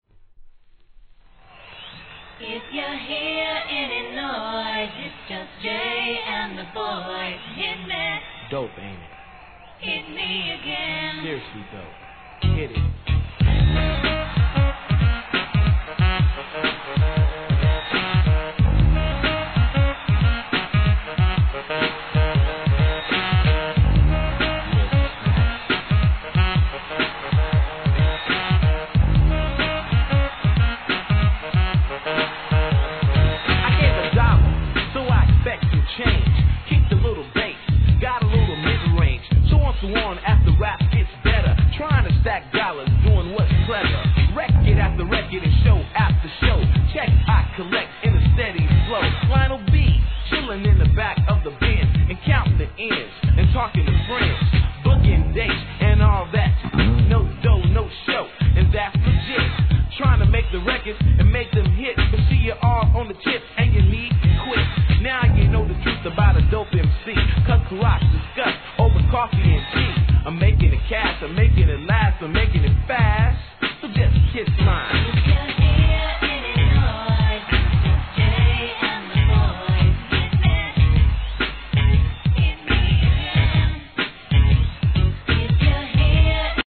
1. HIP HOP/R&B
SAXイントロにベースが効いたなかなか渋い逸品。